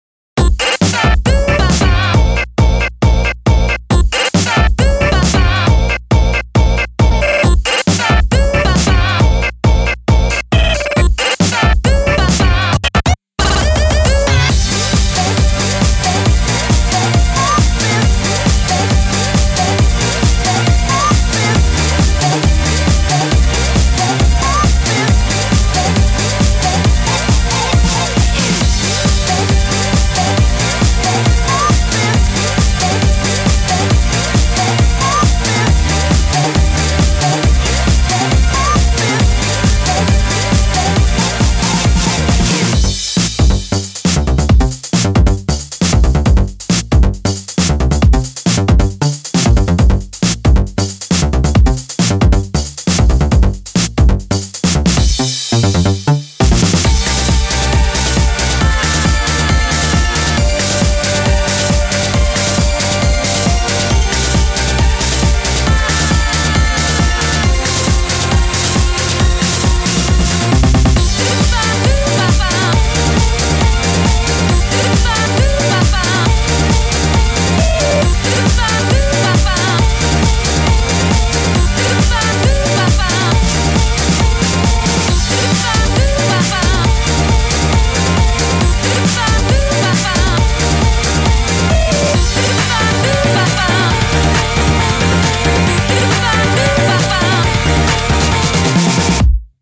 BPM136
Audio QualityPerfect (High Quality)
electro house track